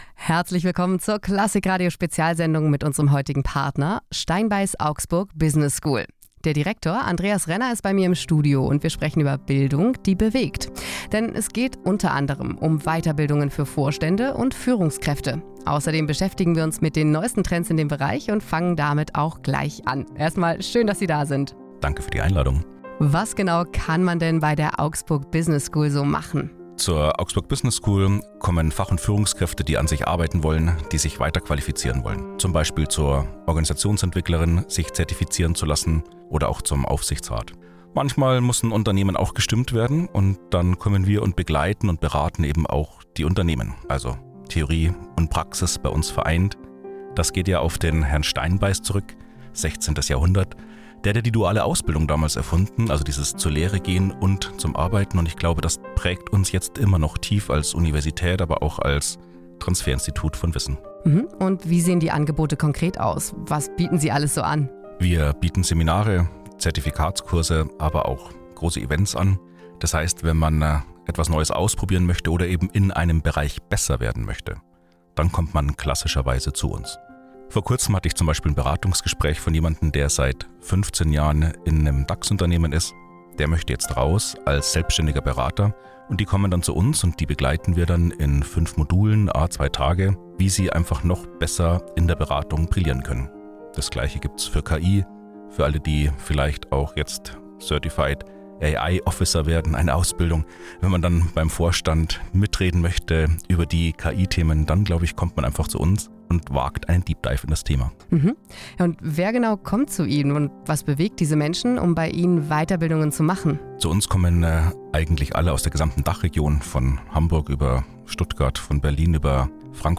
Klassik Radio Interview